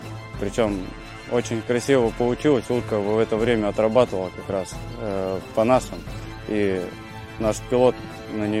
mine-clearing vehicle hit while firing